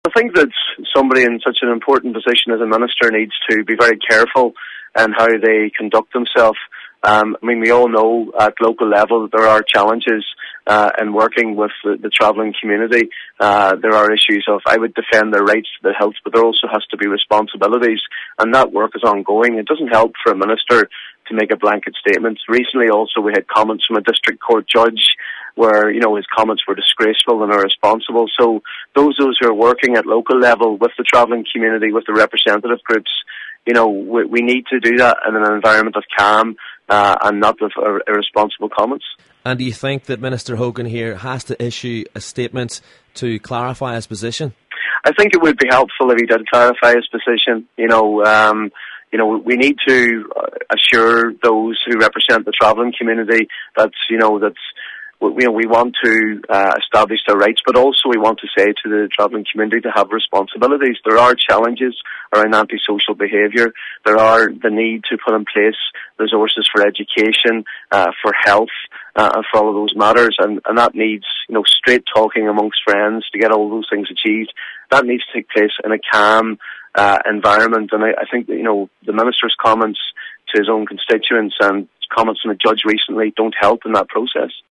Sinn Féin’s Donegal North-East Deputy Padriag MacLochlain wants a Dáil statement from Minister Hogan.
And he’s calling on the Minister to clarify his position: